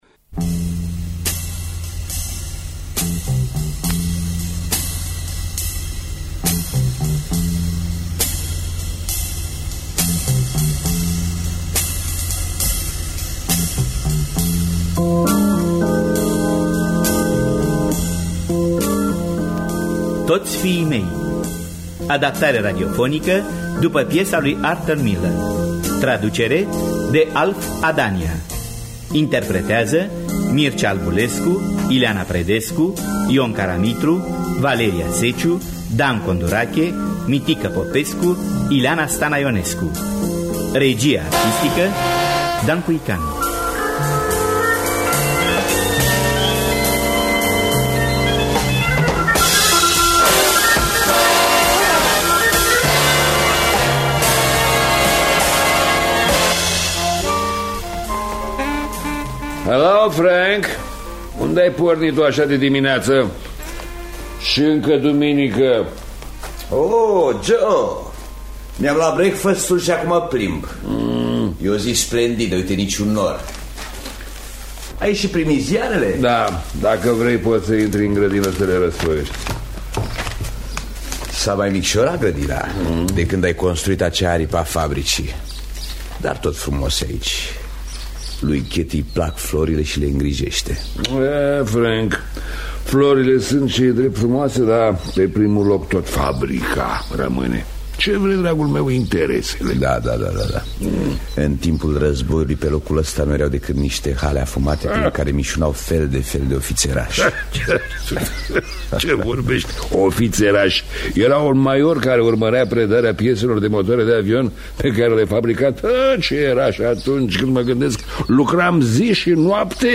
Toți fiii mei de Arthur Miller – Teatru Radiofonic Online